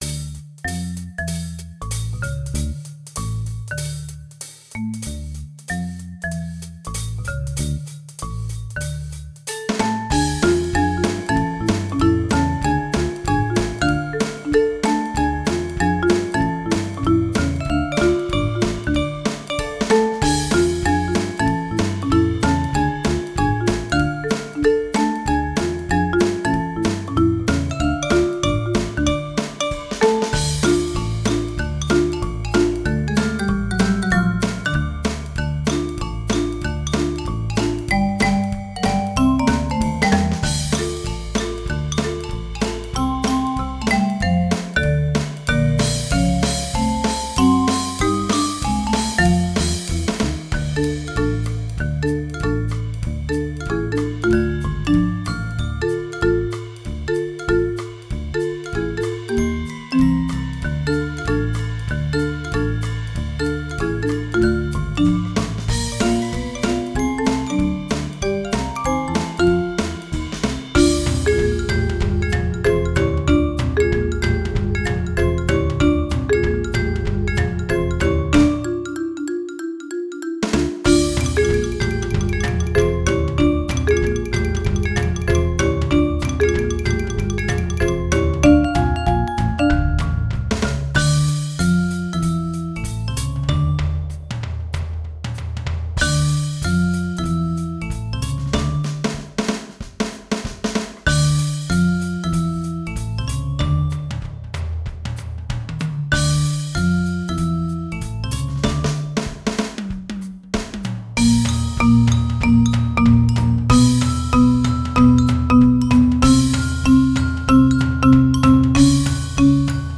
Gattung: Für Schlagzeug Ensemble
Besetzung: Instrumentalnoten für Schlagzeug/Percussion